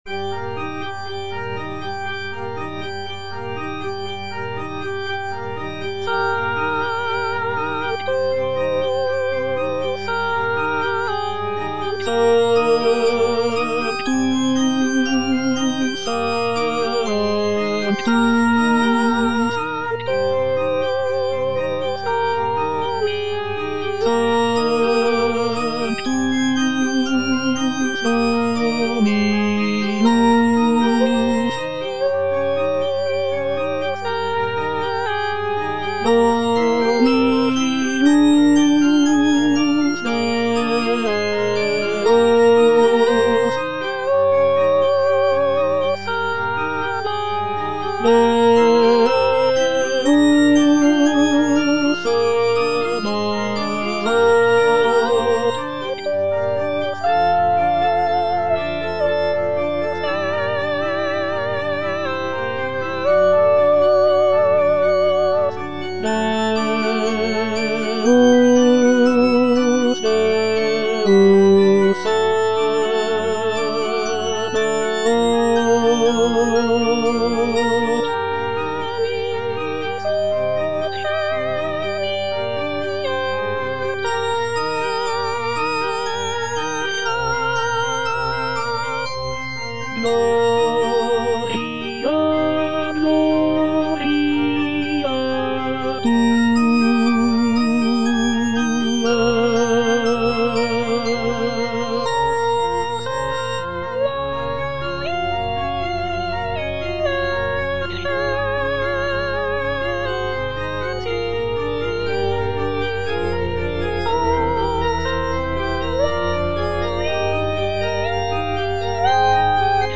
version with a smaller orchestra
All voices